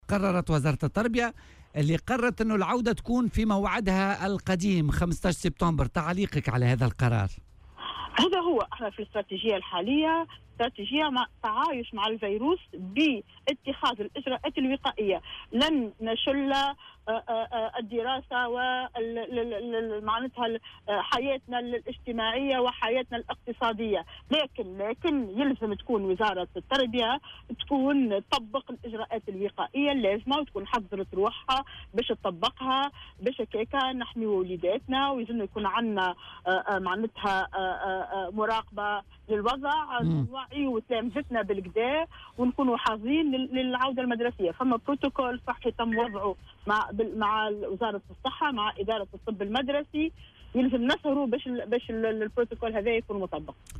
وأضافت في مداخلة لها اليوم على "الجوهرة أف أم" أن الاستراتيجية الحالية ترتكز على مبدأ التعايش مع الفيروس مشدّدة عل ضرورة تطبيق الاجراءات الوقائية اللازمة لحماية للتلاميذ واحترام البروتوكول الصحي الذي تم إعداده بالتعاون مع إدارة الطب المدرسي.